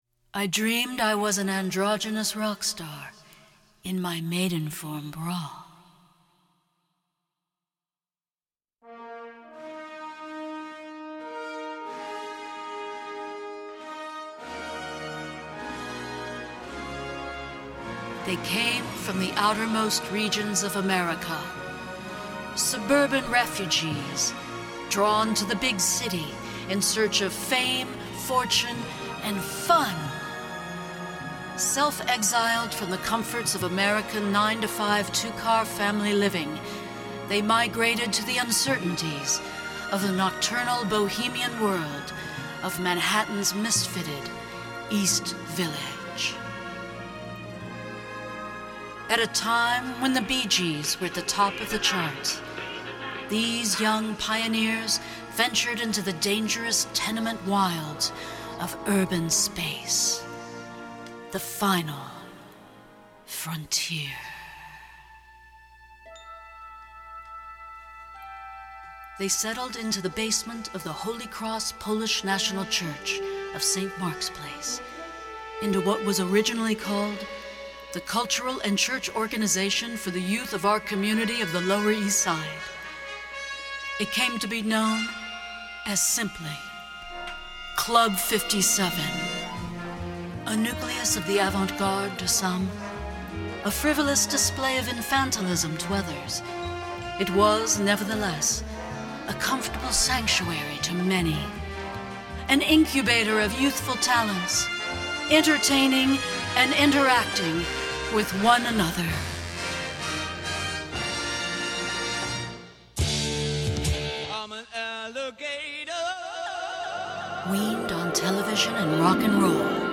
Avant-Garde Spoken Word